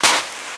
High Quality Footsteps
STEPS Sand, Walk 15.wav